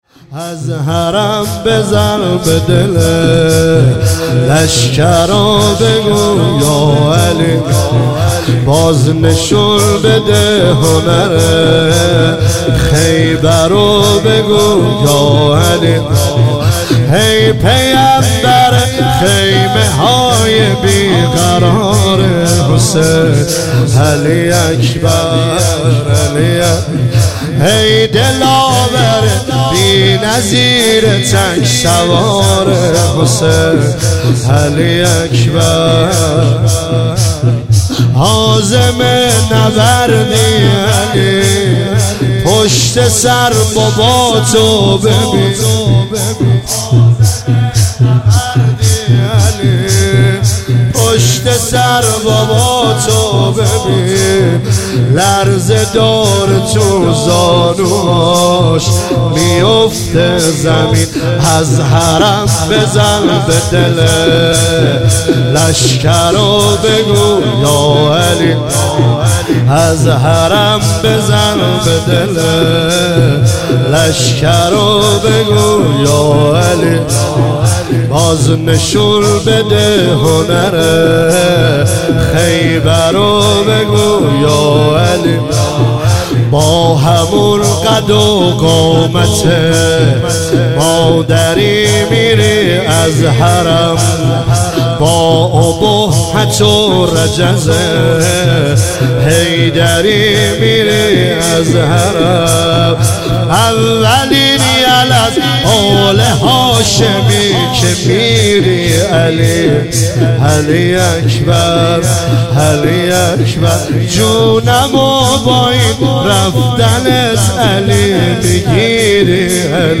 عنوان مراسم هفتگی
زمینه از حرم بزن به دلِ، لشکر و بگو یا علی